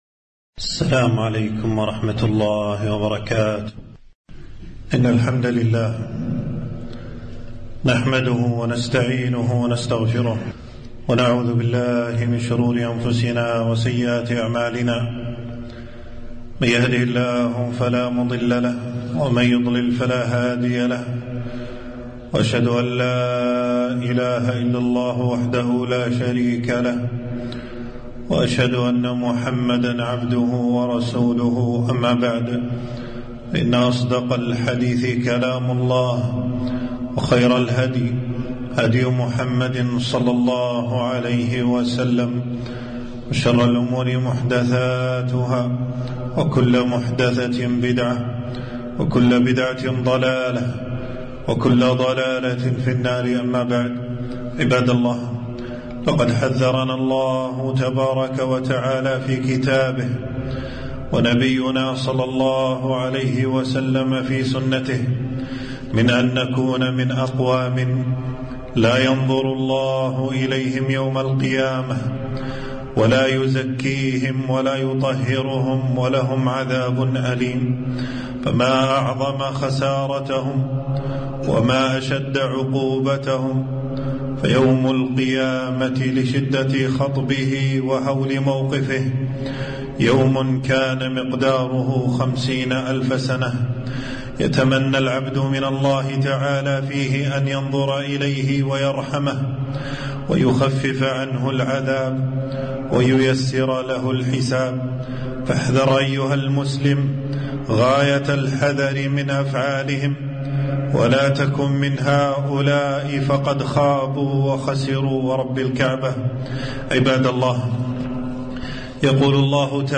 خطبة - أقوام لا يكلمهم الله ولا يزكيهم ولا ينظر إليهم